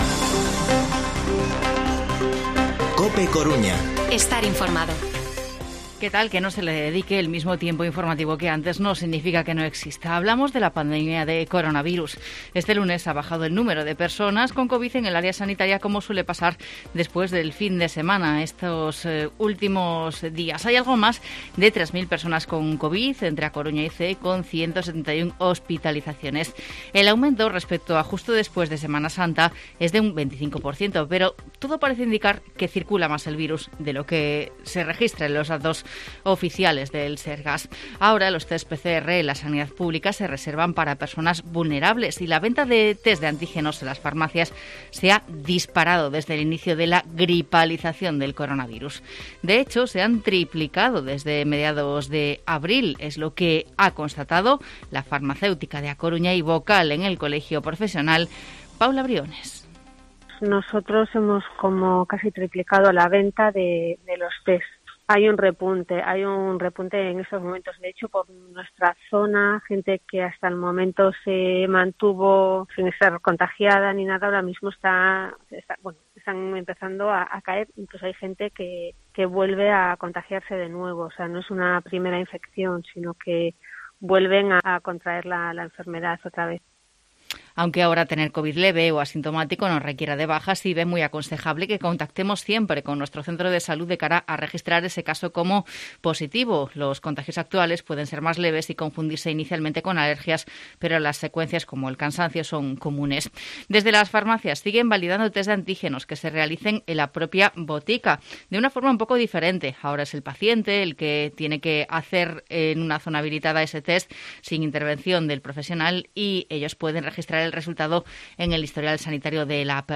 Informativo Mediodía COPE Coruña lunes, 9 de mayo de 2022 14:20-14:30